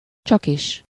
Ääntäminen
Synonyymit kizárólag Ääntäminen Tuntematon aksentti: IPA: /ˈt͡ʃɒkiʃ/ Haettu sana löytyi näillä lähdekielillä: unkari Käännöksiä ei löytynyt valitulle kohdekielelle.